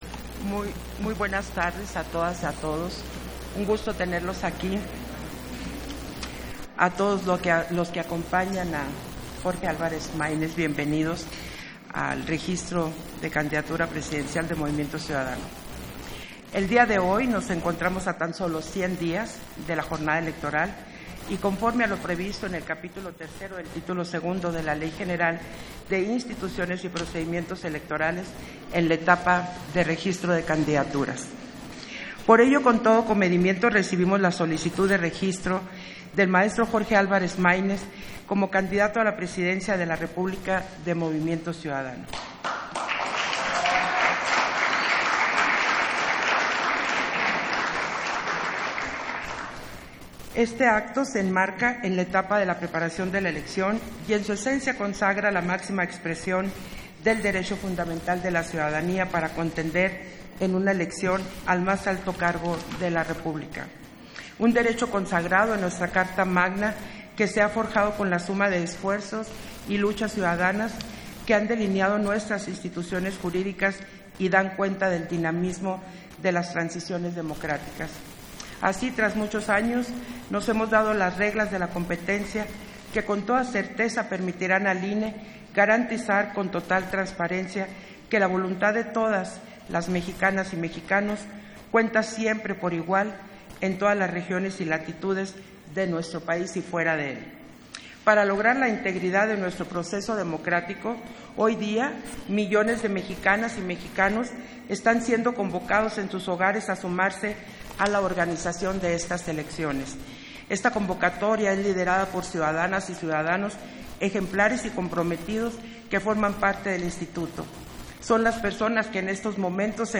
Intervención de Guadalupe Taddei, en el registro de la candidatura a la Presidencia de la República del partido político Encuentro Ciudadano